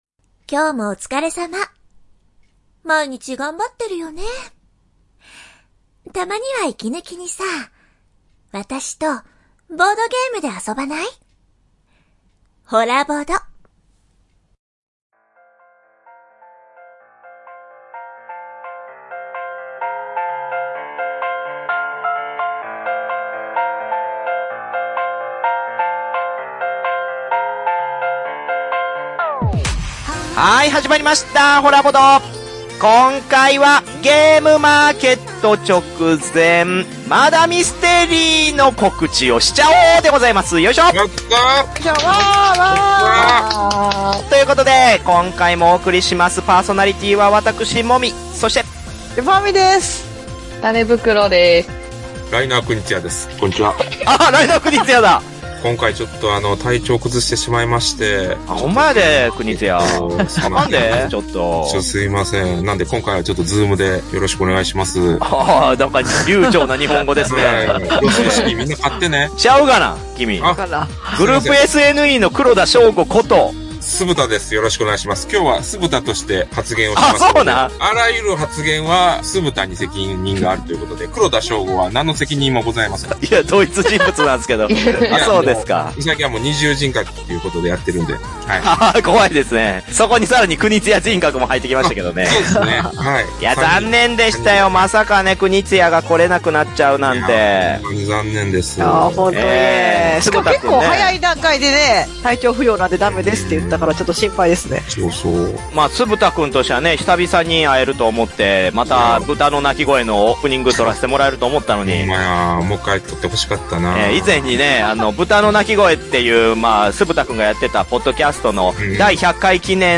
※Zoomによるオンライン収録のため、 途中聴き取り辛い点が多々あります。